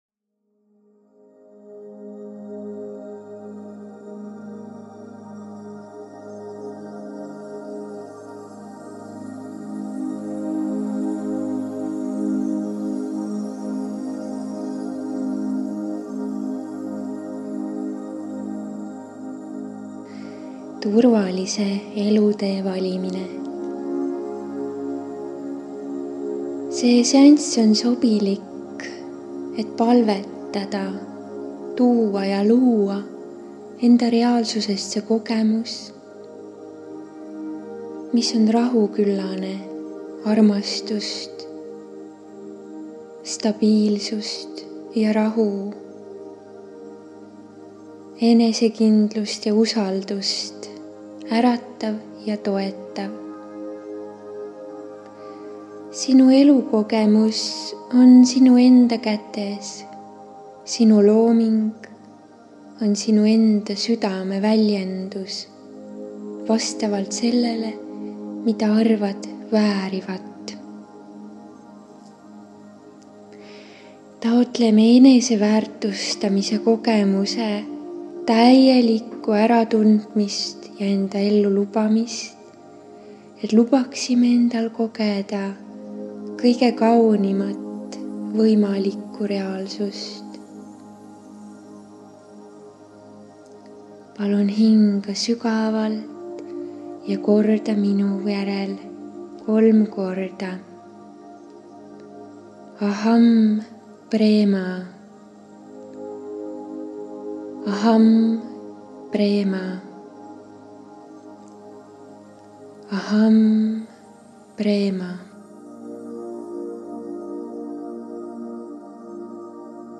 MEDITATSIOON